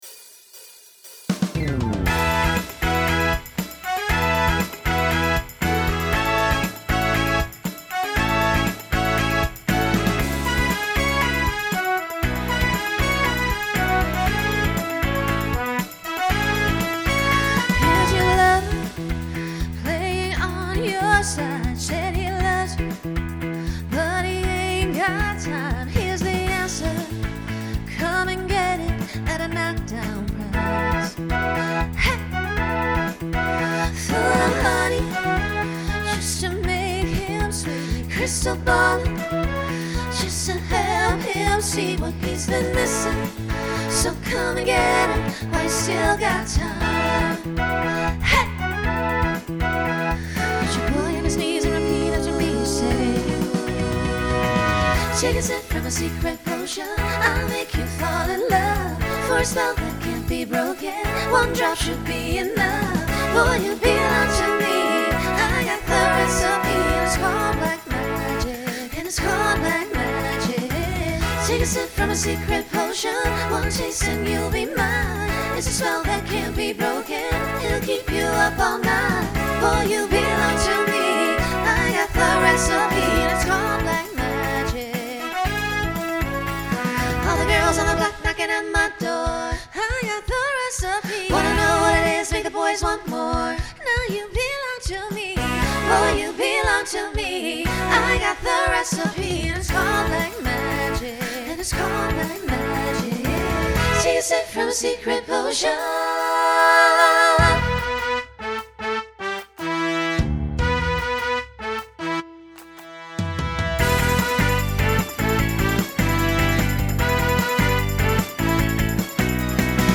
Uses a trio to facilitate costume change.
Genre Pop/Dance
Transition Voicing SSA